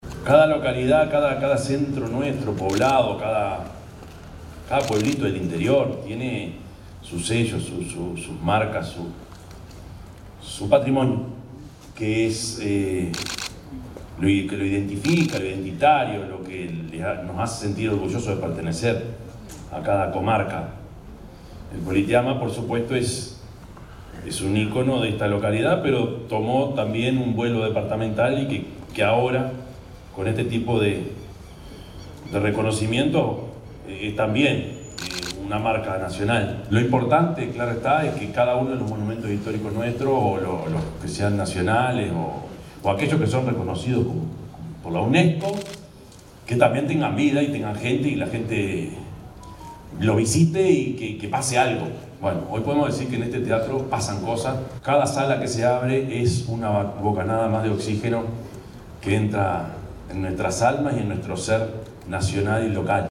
El Complejo Cultural Politeama – Teatro Atahualpa del Cioppo fue declarado Monumento Histórico Nacional
yamandu_orsi_intendente_de_canelones_8.mp3